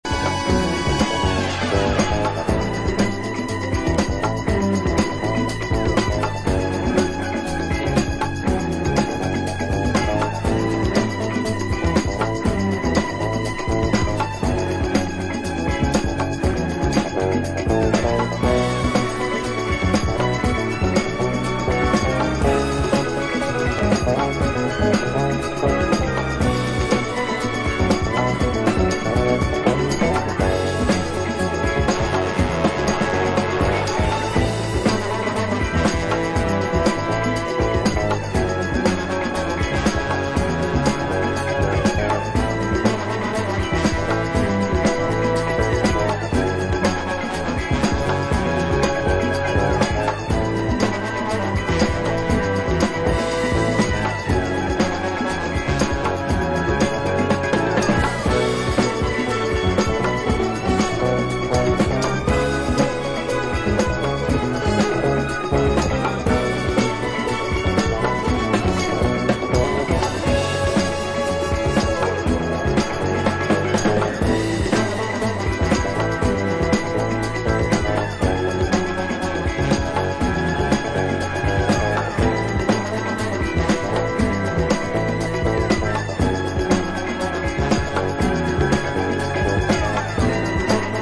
メロディアスなストリングスが印象的なスリル溢れるジャズ・ファンク・クラシックです！